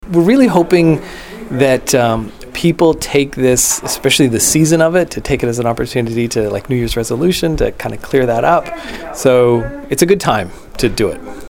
Councillor Rik Logtenberg says he hopes people take advantage of it.